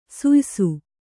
♪ suysu